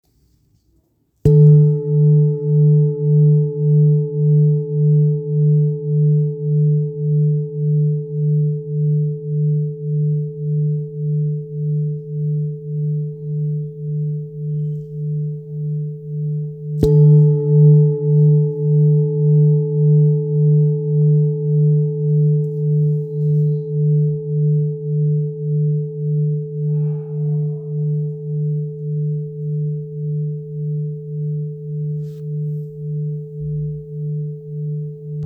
Kopre Singing Bowl, Buddhist Hand Beaten, Antique Finishing
Material Seven Bronze Metal
5 Seconds